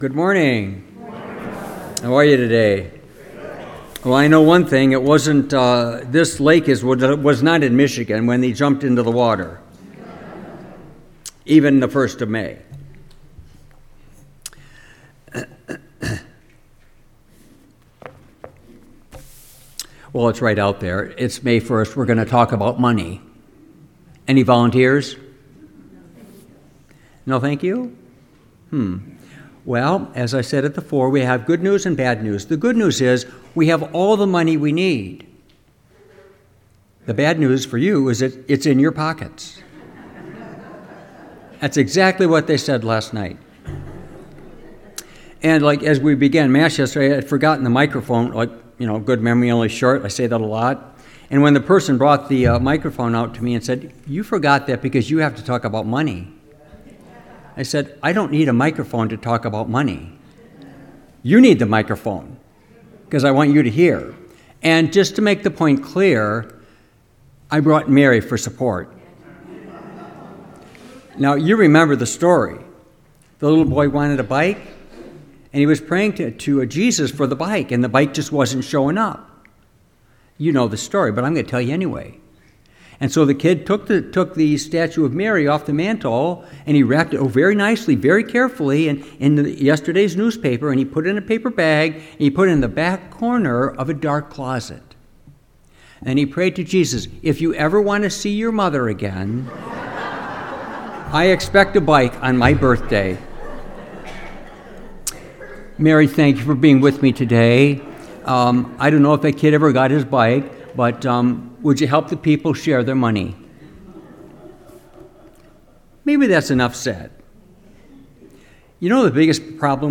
Homily, May 4, 2025